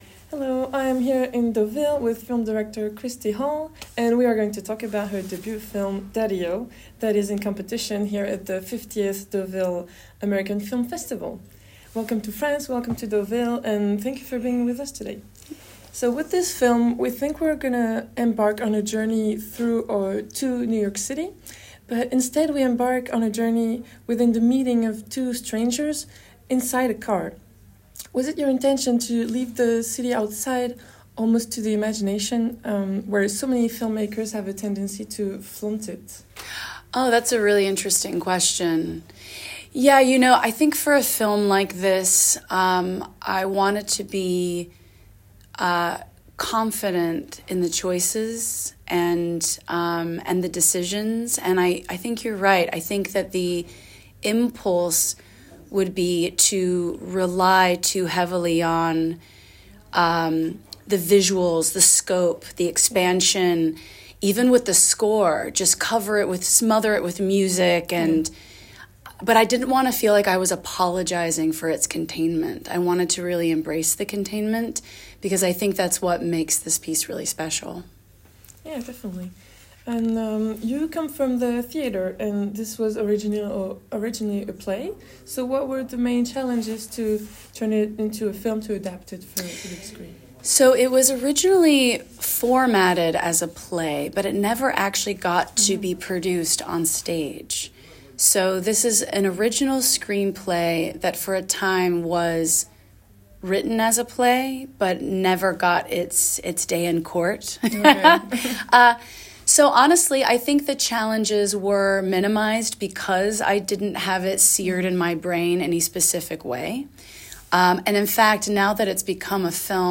%%Les podcasts, interviews, critiques, chroniques de la RADIO DU CINEMA%% La Radio du Cinéma Deauville 2024: